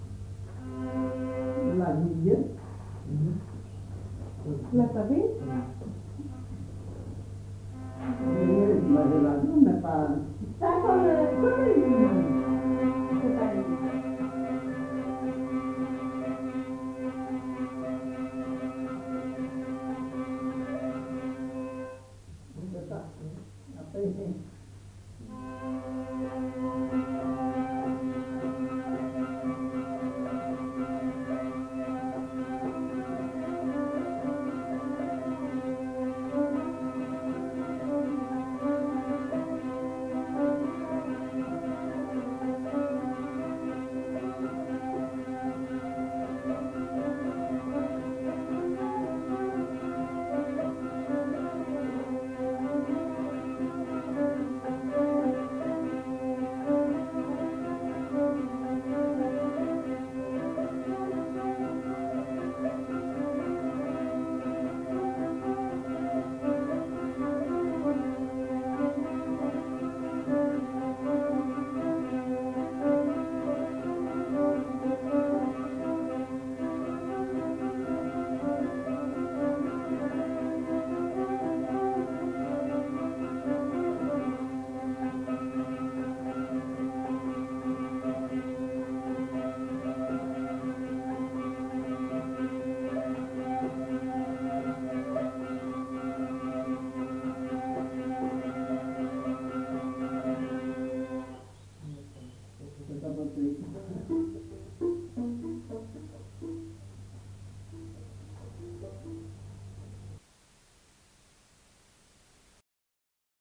Aire culturelle : Petites-Landes
Genre : morceau instrumental
Instrument de musique : vielle à roue ; violon
Danse : gigue